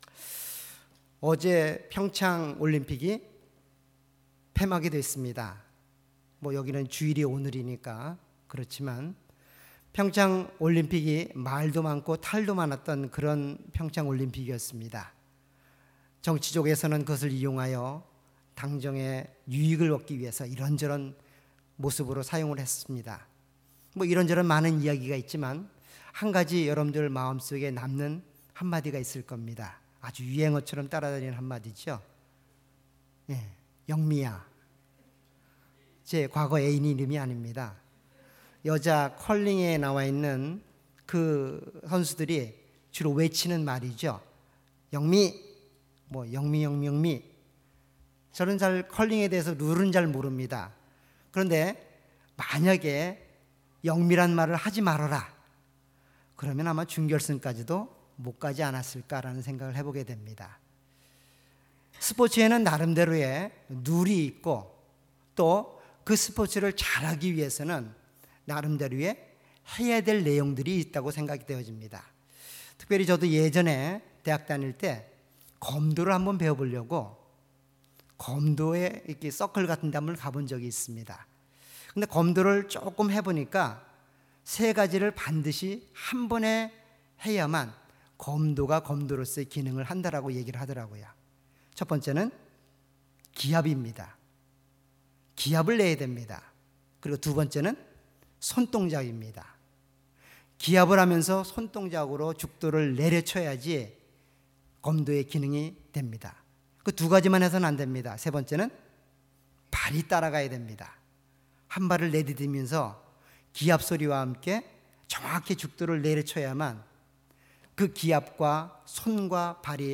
All Sermons
Series: 주일예배.Sunday